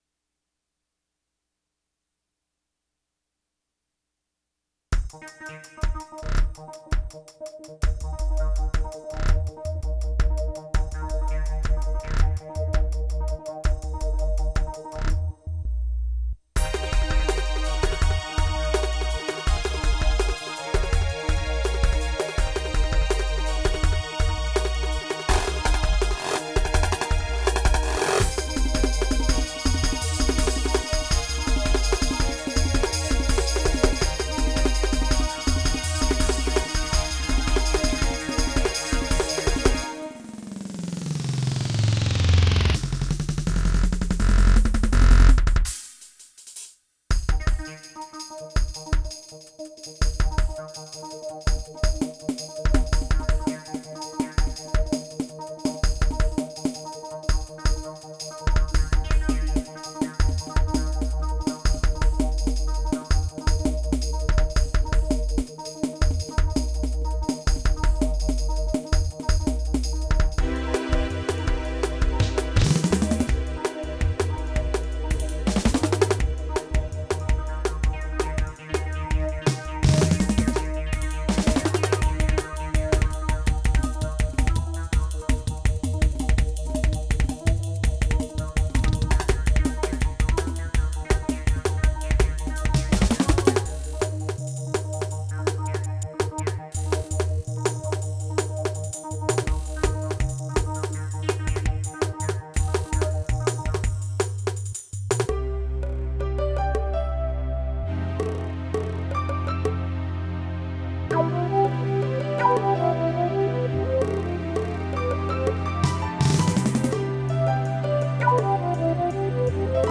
recorded on a Terratec DMX6Fire 24/96.